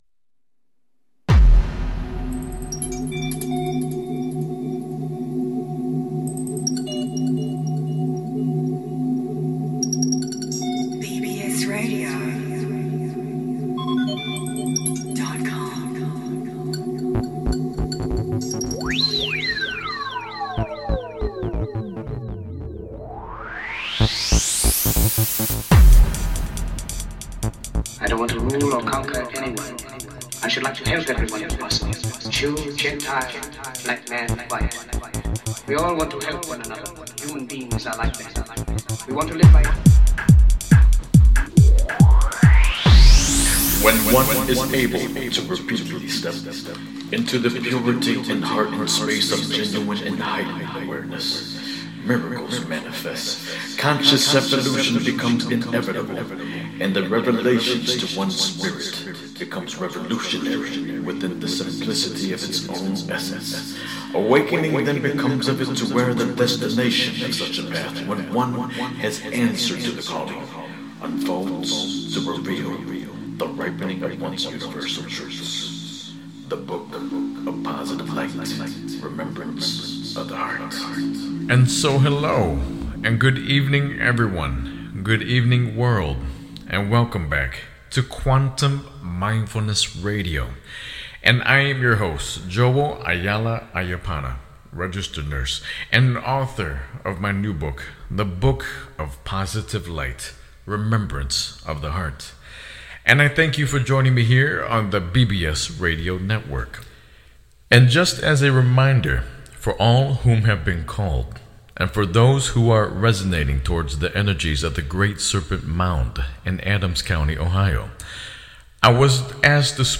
Headlined Show, Quantum Mindfulness Radio May 12, 2014